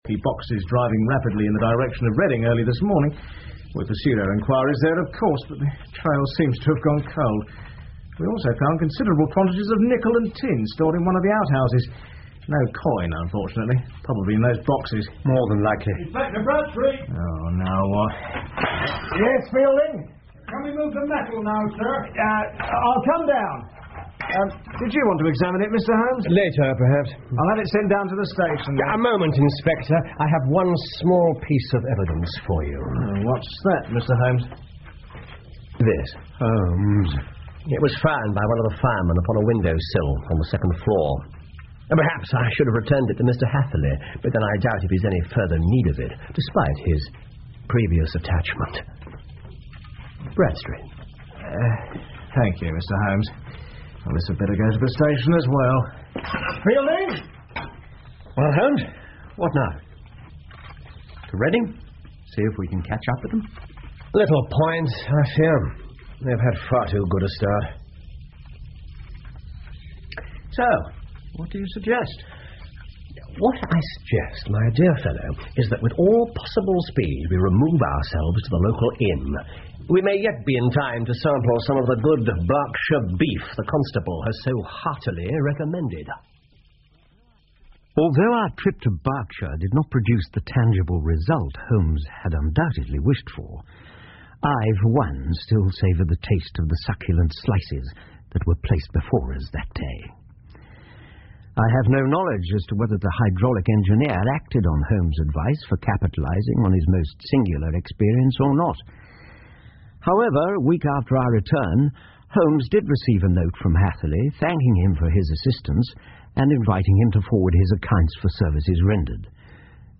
福尔摩斯广播剧 The Engineer's Thumb 9 听力文件下载—在线英语听力室